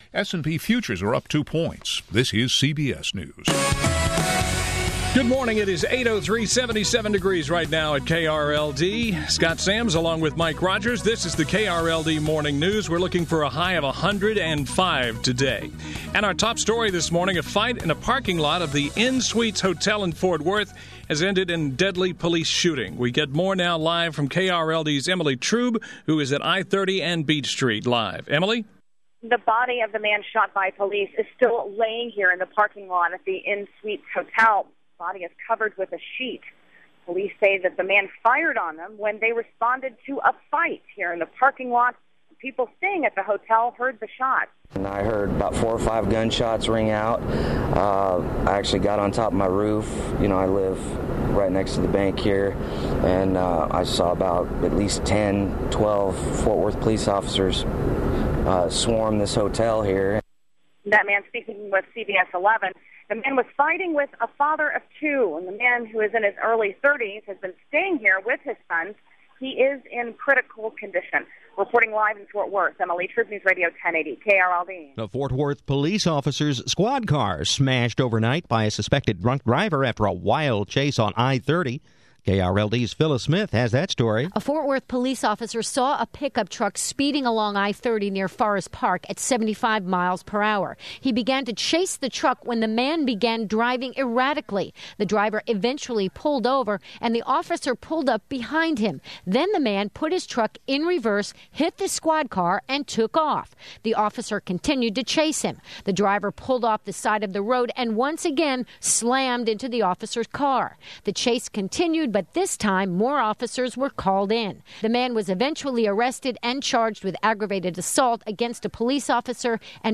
Best Newscast - 6-26-12 8am